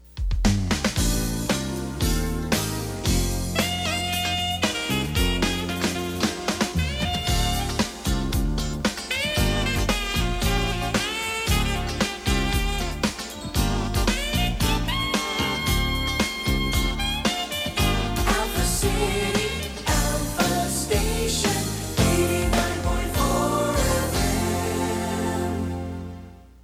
音源は全てステレオ録音です。
全体を通しての感想ですが、ジャズを基調としたとても贅沢な楽曲となっています。